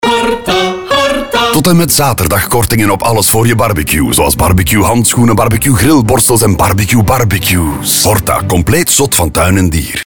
Vier radiospots die opnieuw de zintuigen prikkelen.